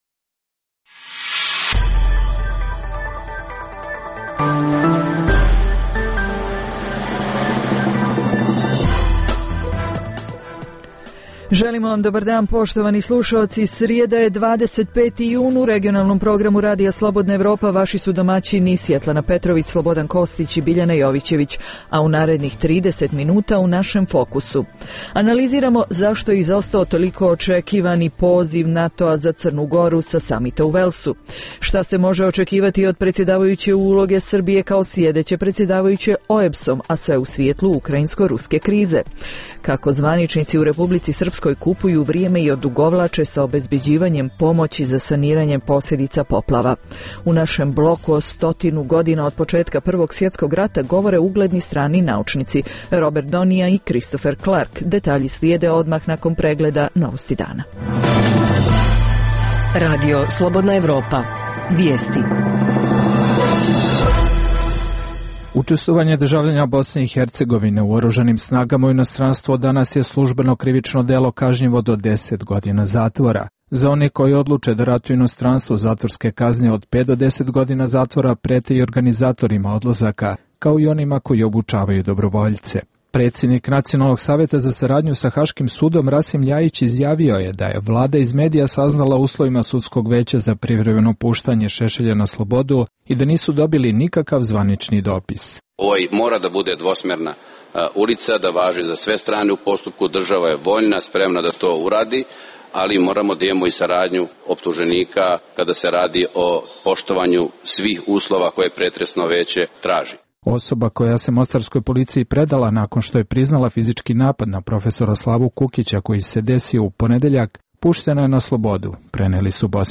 Dnevna informativna emisija Radija Slobodna Evropa o događajima u regionu i u svijetu.